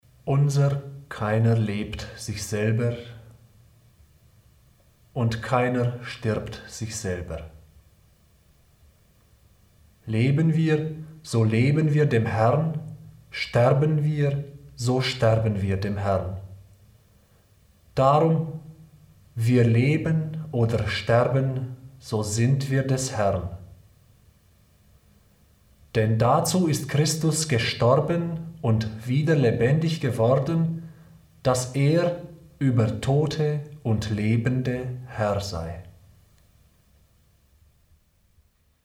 – Lesung Römerbrief (275 Downloads )